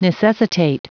Prononciation du mot necessitate en anglais (fichier audio)
Prononciation du mot : necessitate